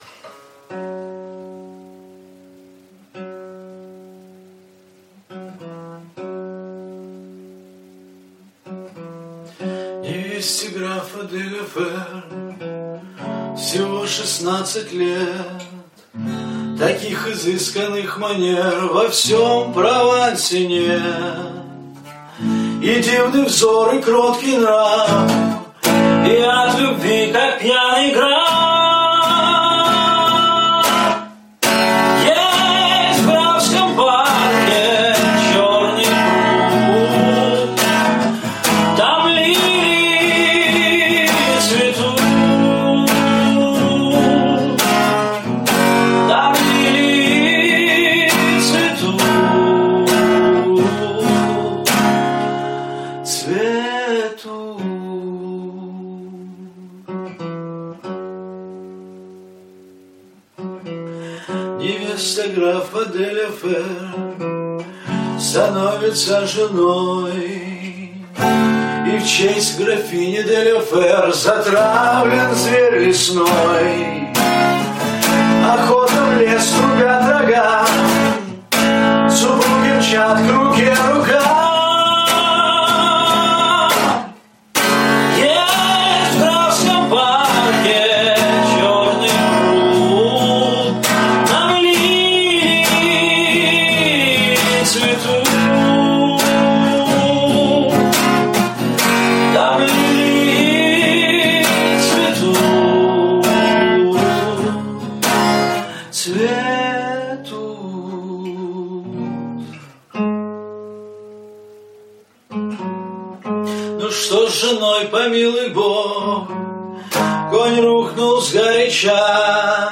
(живьё)